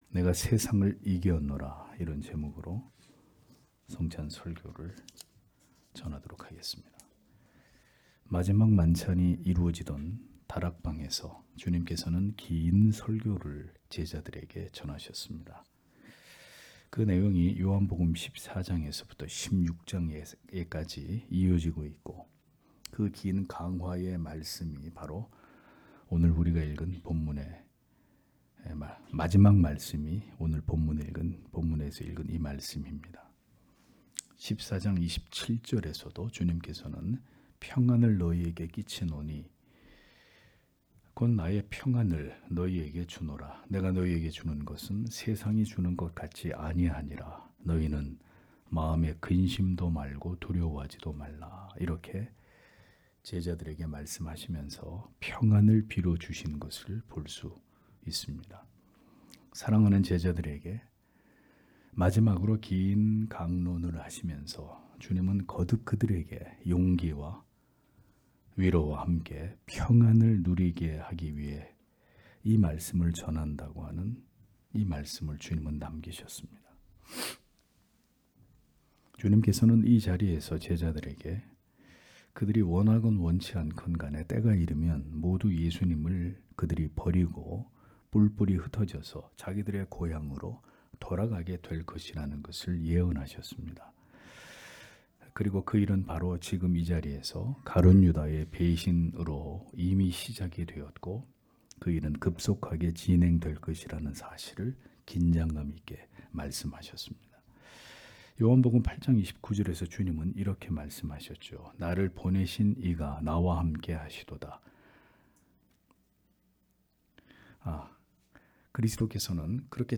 주일오전예배 - [성찬 설교] 내가 세상을 이기었노라 (요 16장 32-33절)